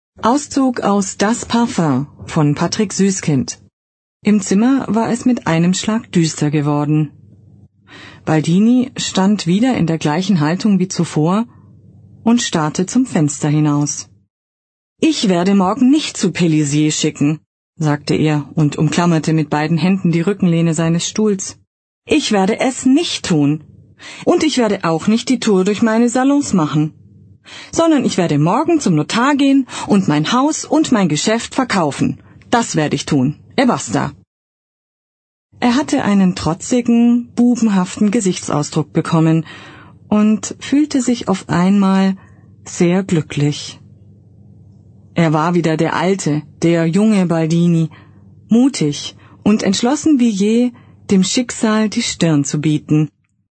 Mein großes Plus: absolut sympathische Frauenstimme, da tief & voll Ich freue mich auf Ihren Kontakt!
deutsche Sprecherin für Werbespots, Hörbücher, Emotionales, Telefonansagen.
Sprechprobe: Werbung (Muttersprache):
german female voice over talent